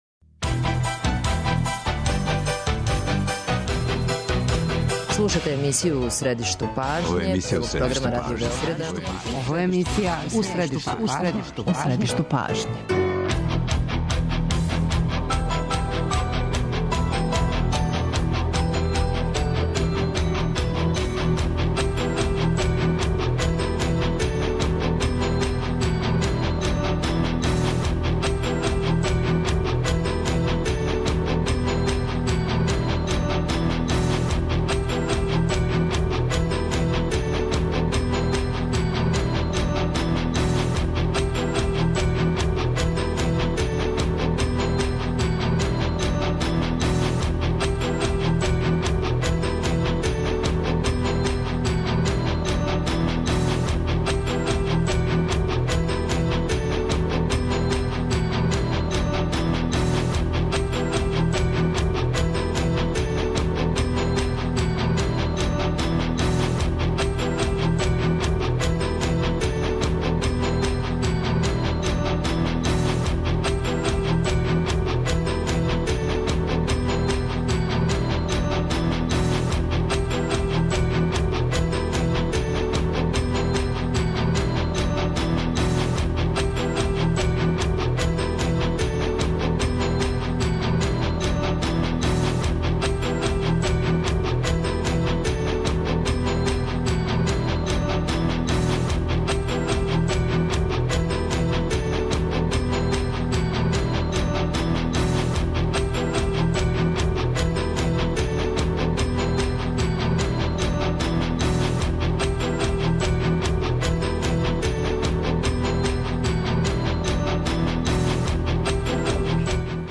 Гости емисје су бивши селектор Слободан Сантрач и садашњи Љубинко Друловић.
Потсетићемо се радио преноса наших коментатора са неких претходних шампионата.